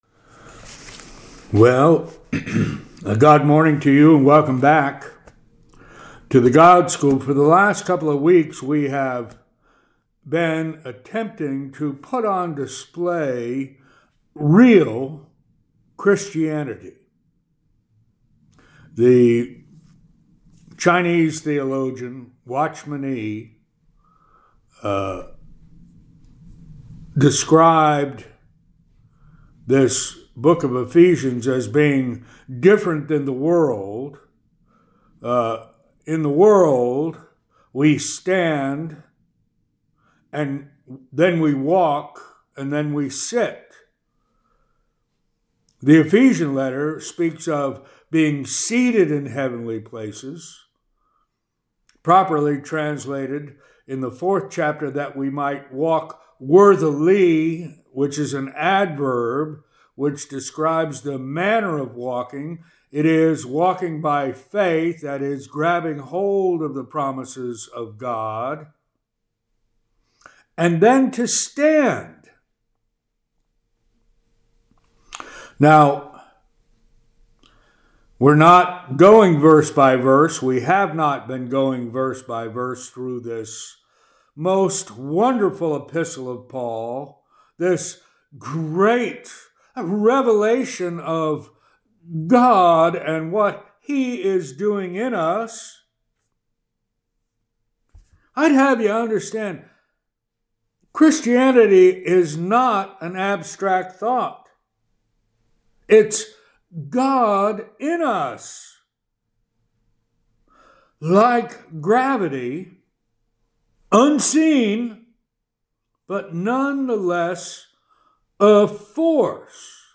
Weekly Teaching